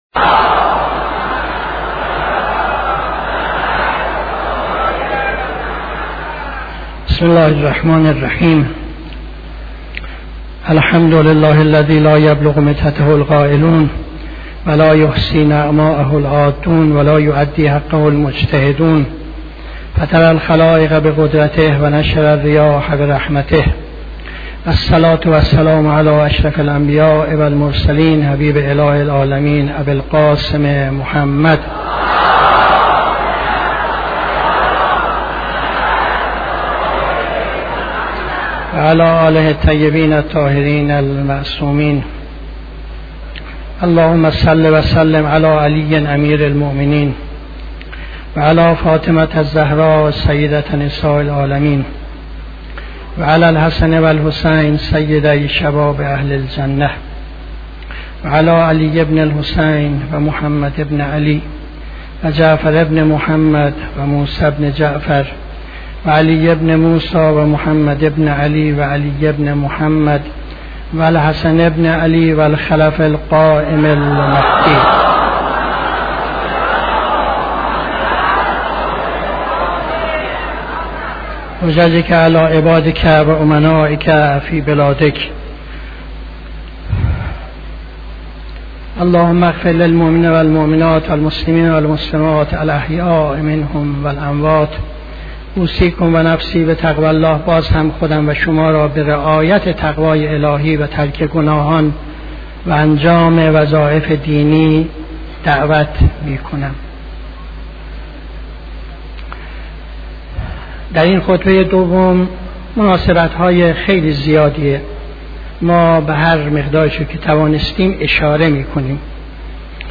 خطبه دوم نماز جمعه 24-12-75